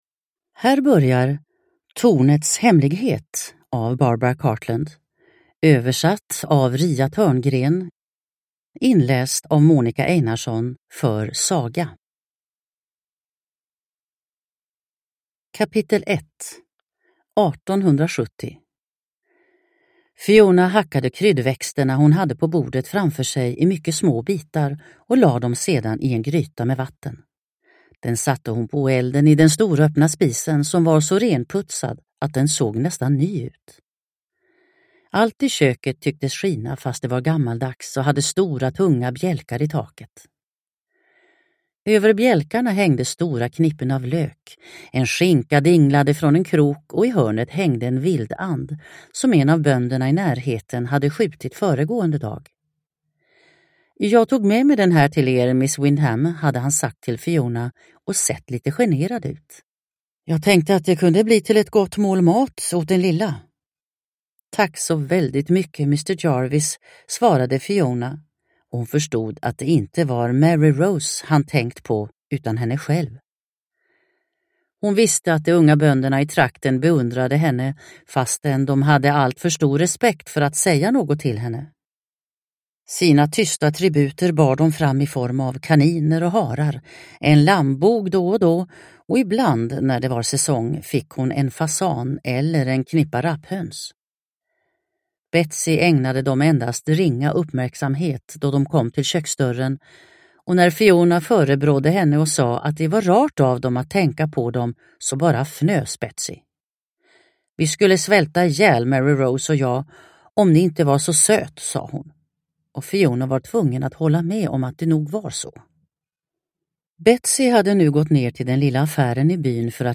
Tornets hemlighet / Ljudbok